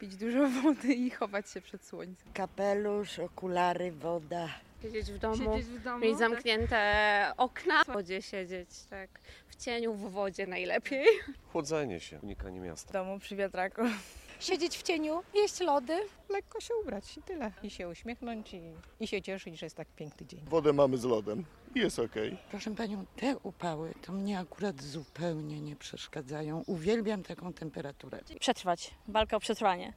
Jak radzić sobie z upałami? – zapytaliśmy Dolnoślązaków.